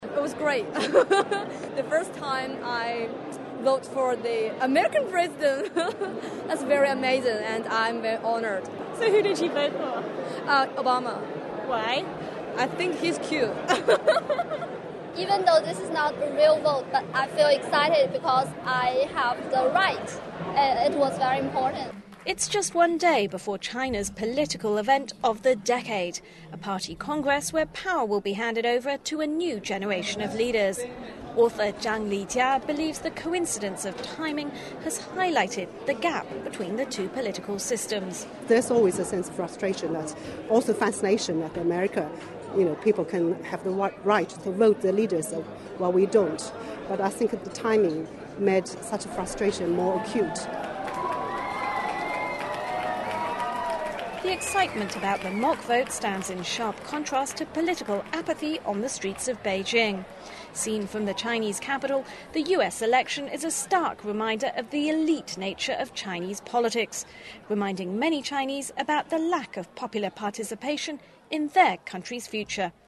This NPR clip is from several Chinese students who had a chance to vote for the U.S. President in a mock election at the U.S. Embassy selection party in Beijing, China. Our democratic elections stand in stark contrast to the upcoming transfer of power within the Communist Party which will take place in China over the next several weeks.